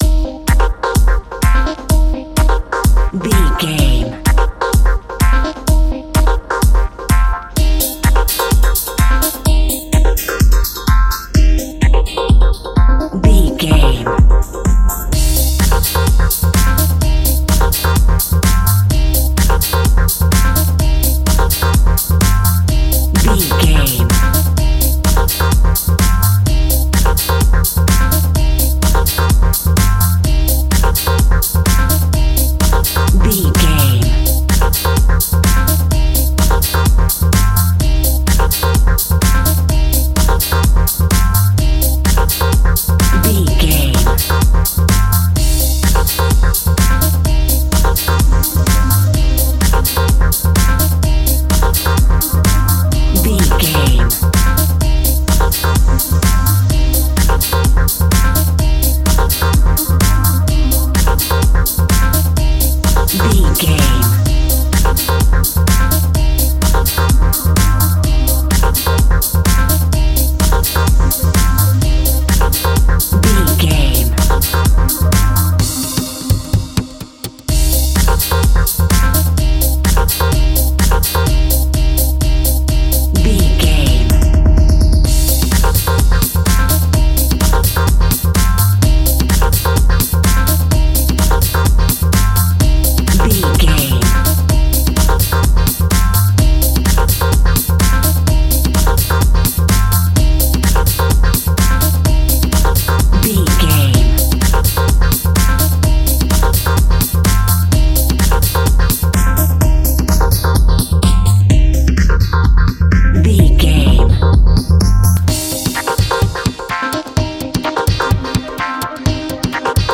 Aeolian/Minor
groovy
hypnotic
uplifting
drum machine
bass guitar
electric guitar
funky house
electro funk
energetic
upbeat
synth bass
Synth Pads
electric piano
clavinet
horns